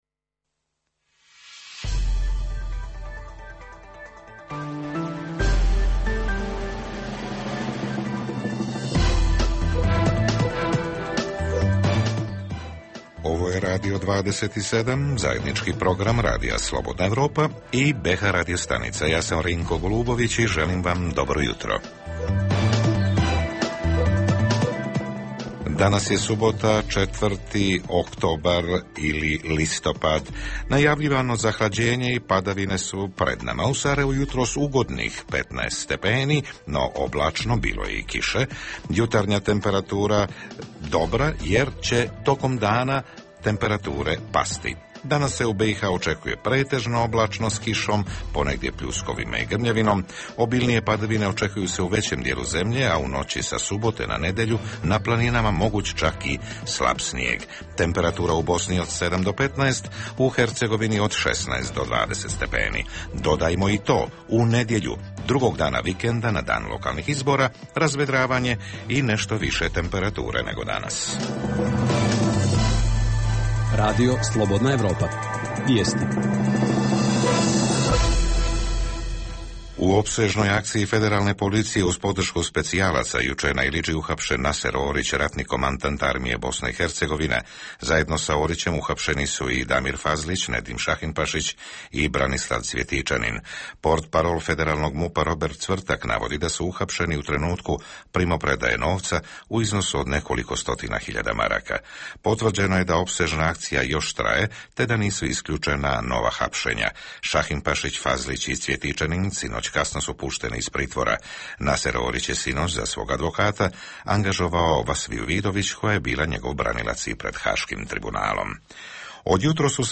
Jutarnji program za BiH koji se emituje uživo. Ovog jutra pitamo da li je sve spremno za lokalne izbore.
Redovni sadržaji jutarnjeg programa za BiH su i vijesti i muzika.